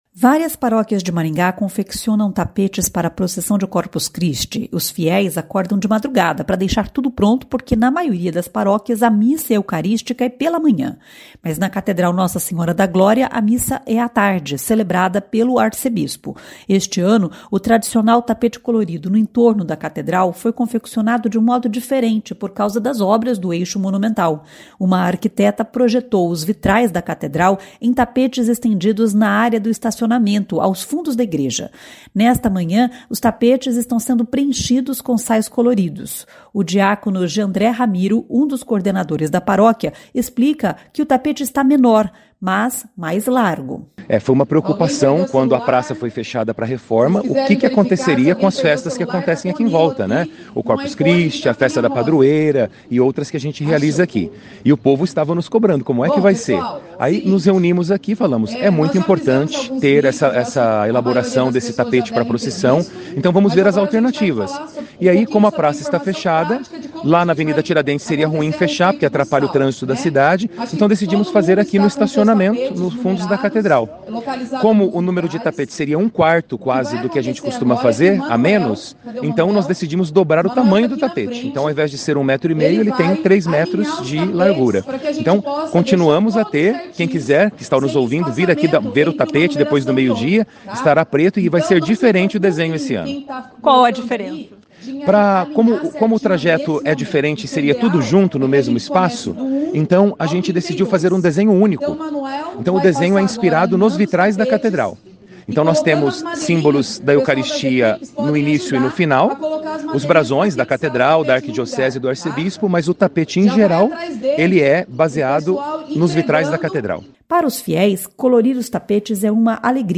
Nesta manhã os tapetes estão sendo preenchidos com sais coloridos.
Ela chorou ao lembrar que nesta madrugada passou mal e quase não conseguiu participar.